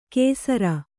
♪ kēsara